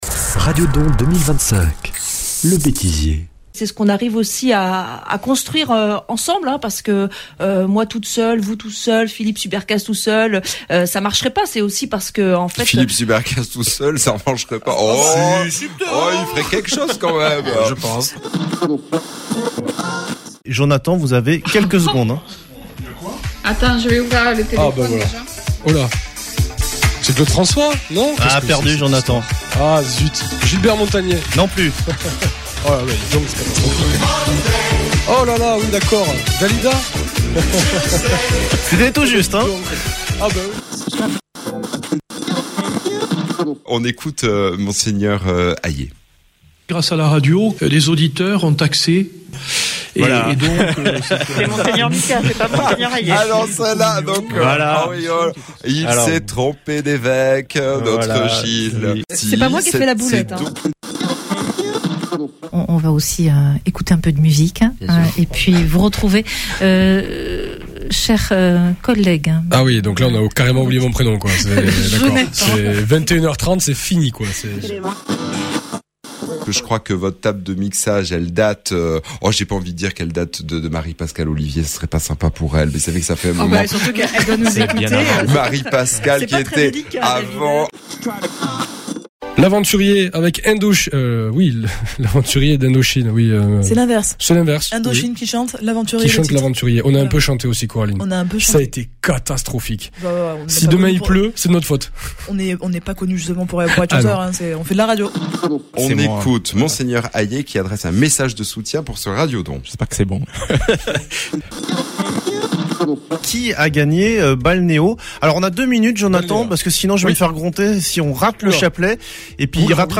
Plongez dans les coulisses les plus inattendues du Radio Don 2025 ! Entre lapsus irrésistibles, improvisations improbables, blagues en direct et petits dérapages techniques, revivez les moments qui ont fait éclater de rire les équipes… et parfois les auditeurs.